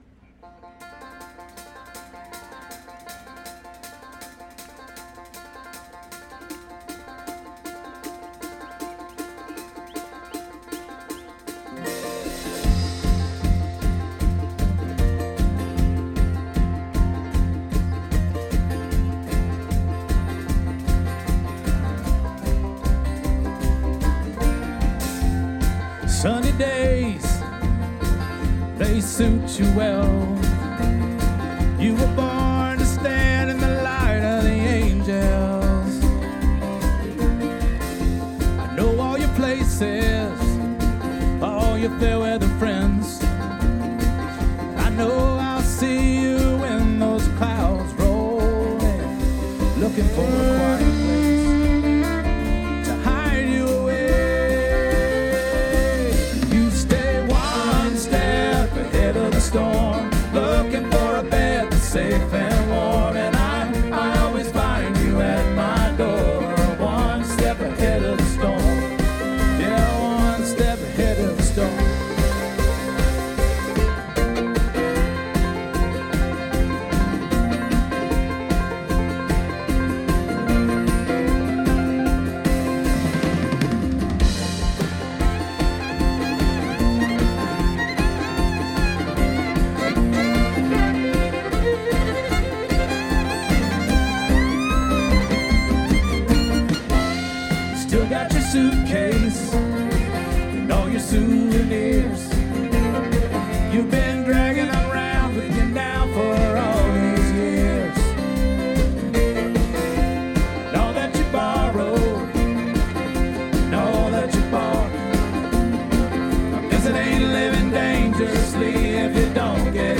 Banjo, Guitar, Harmonica, and Vocals
Mandolin, Mondola and Vocals
Fiddle and Vocals
Bass, Guitar and Vocals
Percussion, Guitar, Dobro, Banjo, Whistling, Vocals,